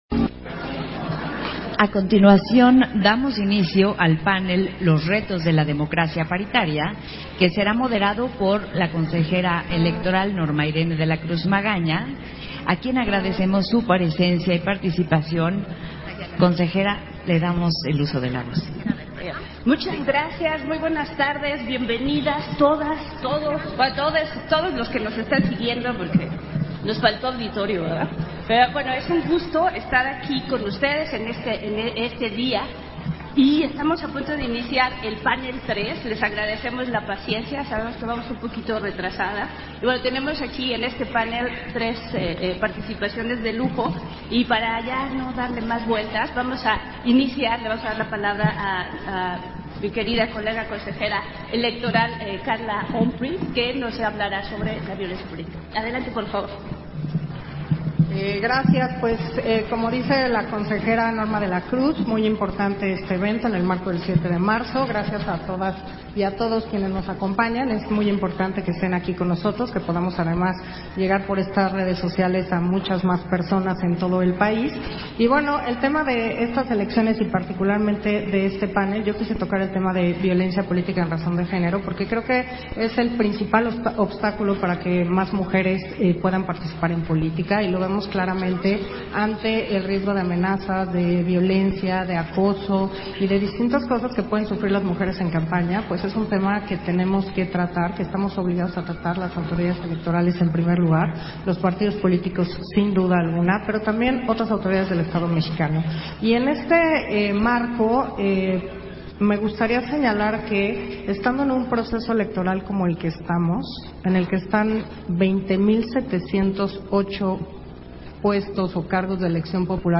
Versión estenográfica del panel 3, Los retos de la democracias paritaria, y conclusiones, en el marco del diálogo entre mujeres a una década de la paridad en México